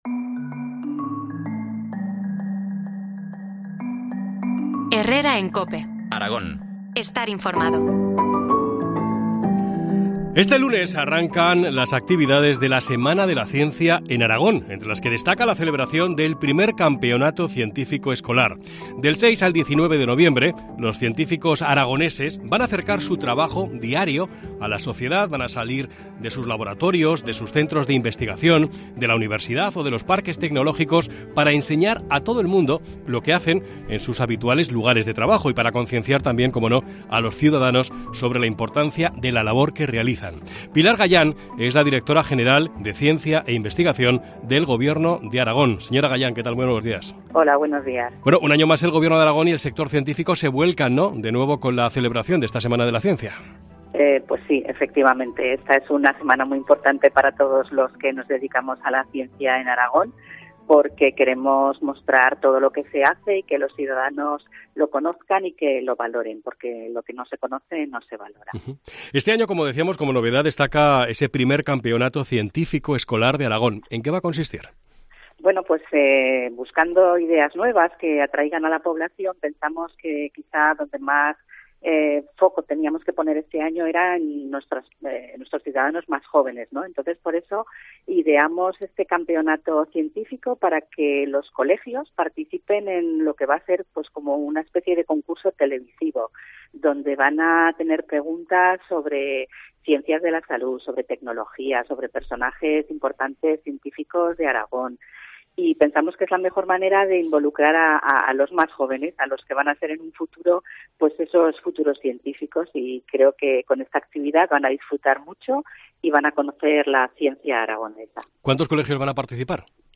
Entrevista sobre la 'Semana de la Ciencia' a la directora general de Ciencia de la DGA, Pilar Gayán.